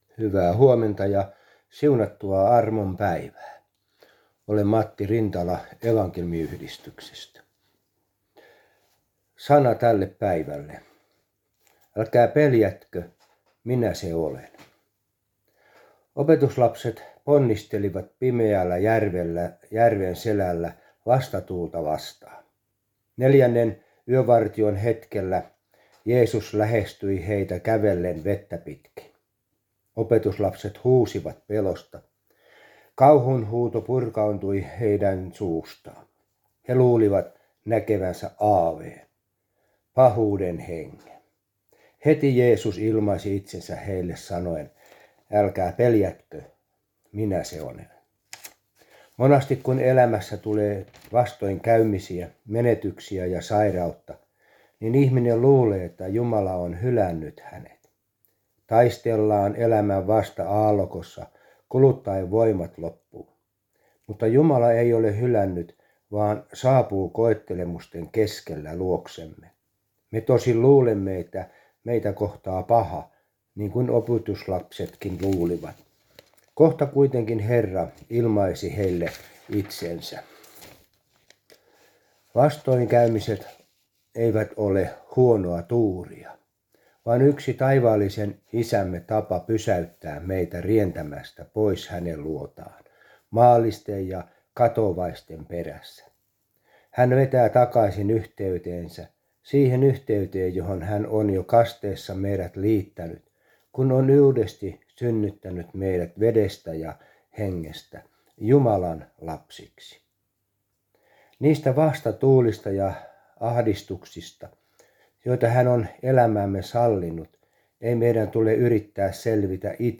Aamuhartaus Järviradioon 6.3.2023
Vetelin Nuorisokuoro